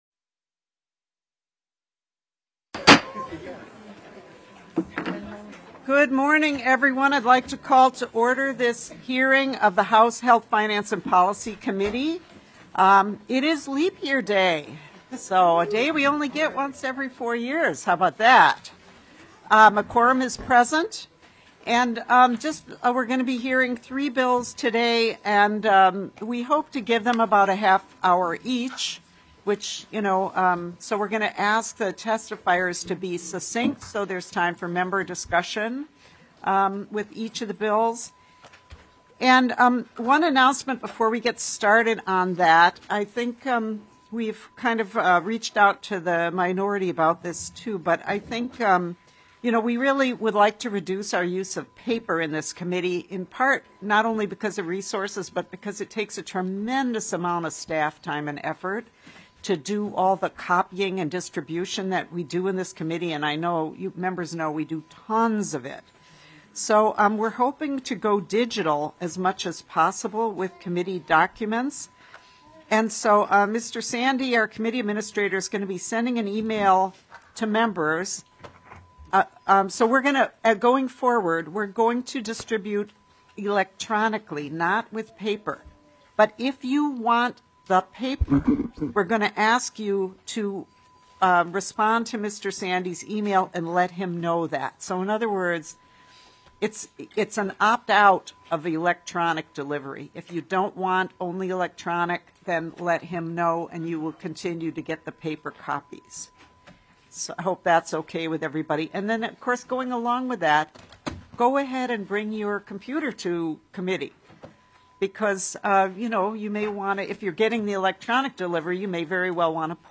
Meeting Details - Monday, March 4, 2024, 1:00 PM
Testimony: